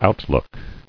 [out·look]